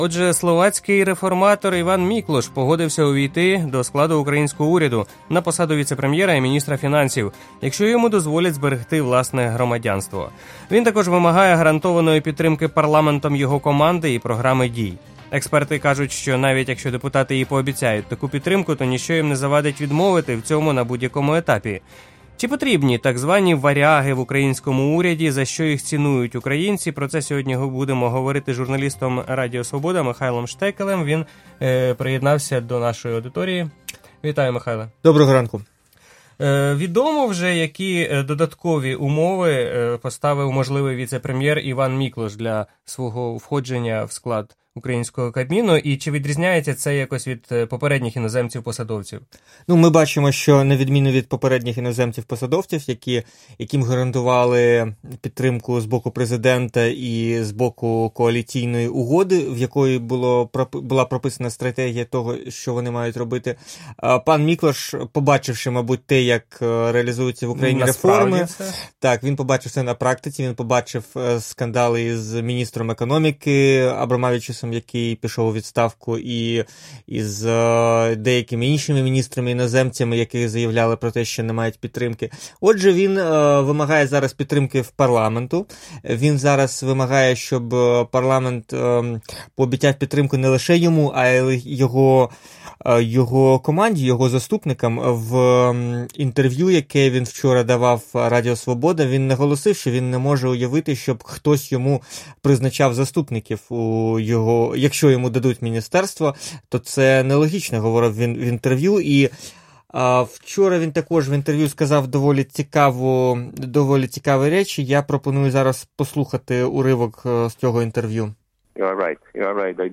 Про іноземців та їх ефективність в урядових структурах України говорили в ефірі «Ранкової Свободи».